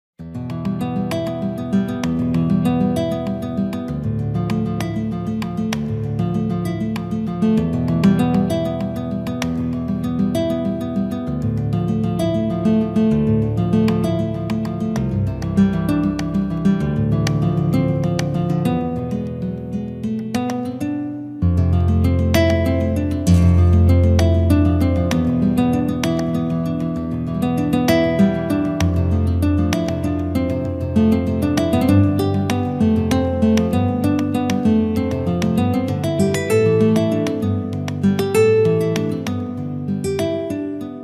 Sonido de Guitarra Española - Sonidos de Instrumentos Musicales.mp3